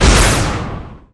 poly_explosion_energynuke2.wav